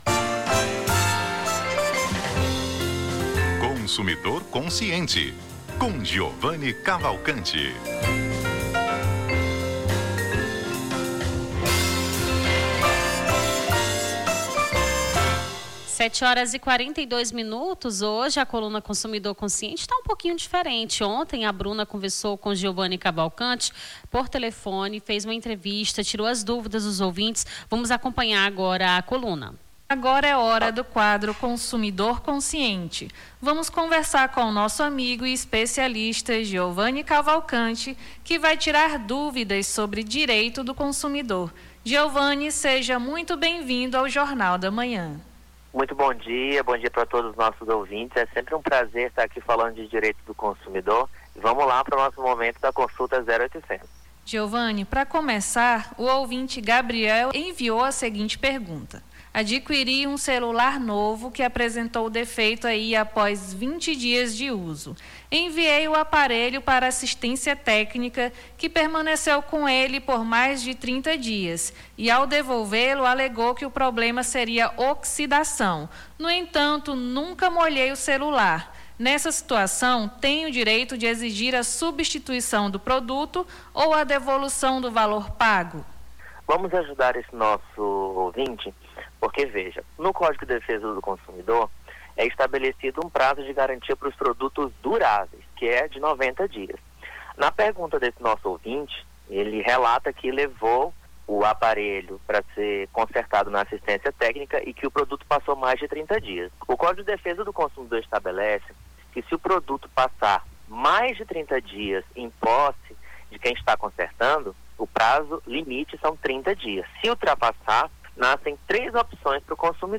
Consumidor Consciente: advogado esclarece dúvidas sobre direito do consumidor